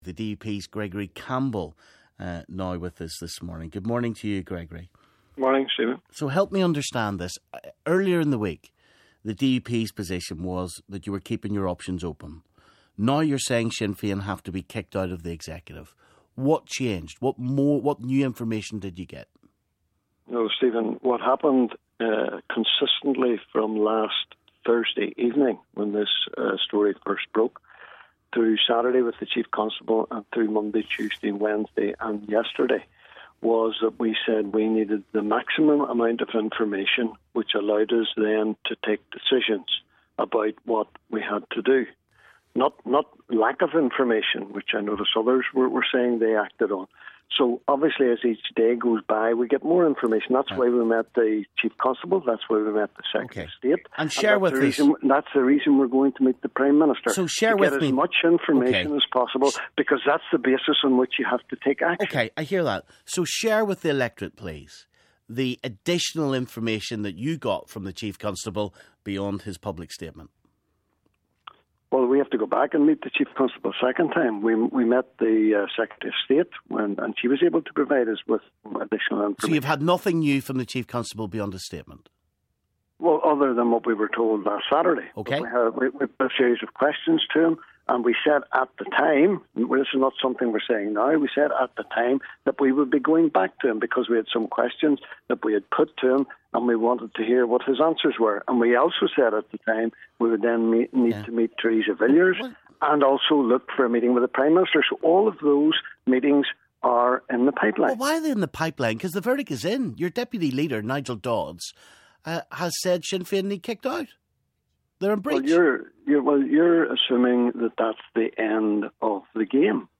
DUP Gregory Campbell joins the discussion.